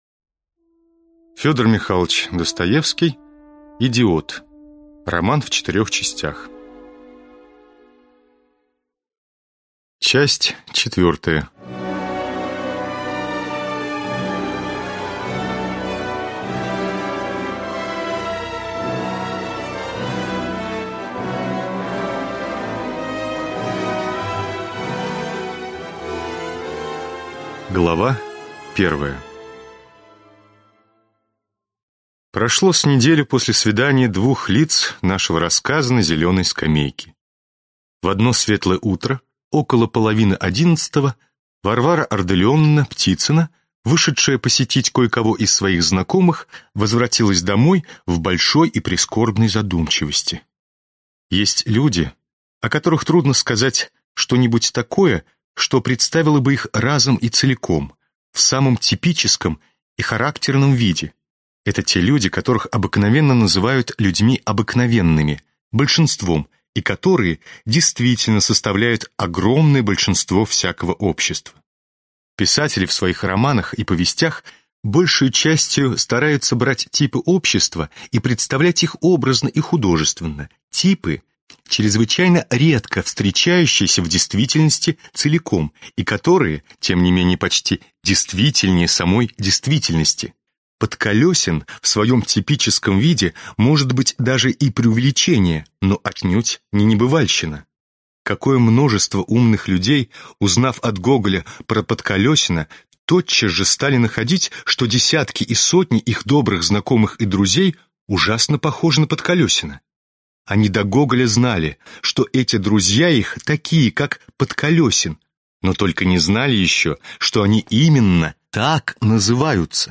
Аудиокнига Идиот (Часть 4) | Библиотека аудиокниг